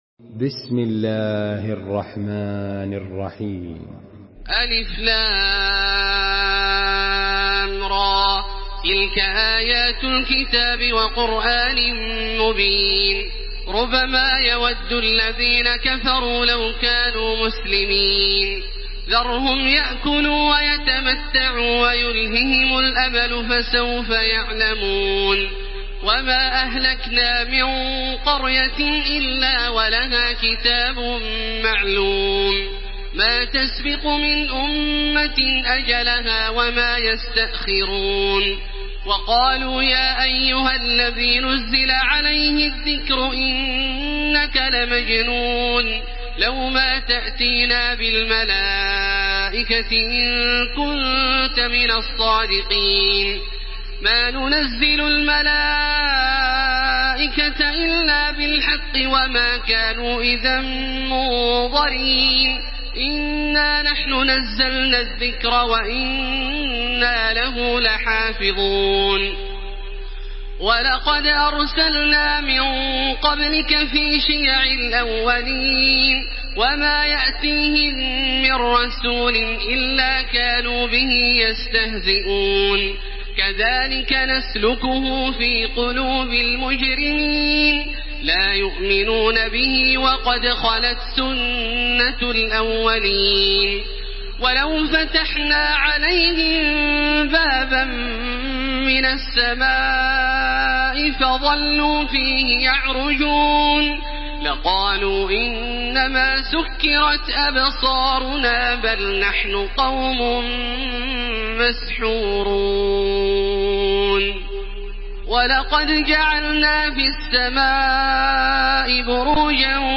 Surah আল-হিজর MP3 in the Voice of Makkah Taraweeh 1434 in Hafs Narration
Murattal Hafs An Asim